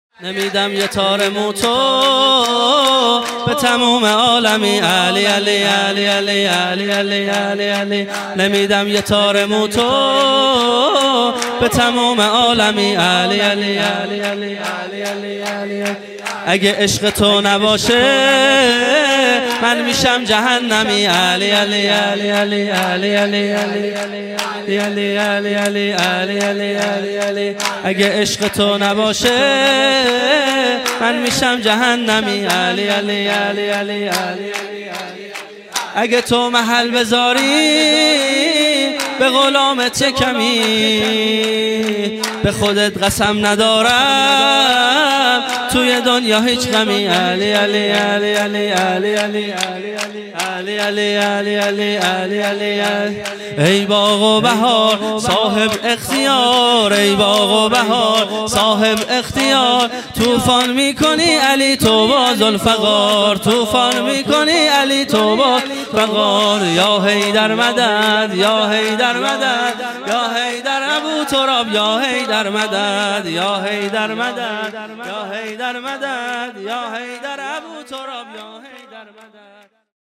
جلسه هفتگی به مناسبت میلاد سرداران کربلا|پنجشنبه 22 فروردین 1398